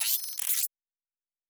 Sci-Fi Sounds / Weapons
Additional Weapon Sounds 5_1.wav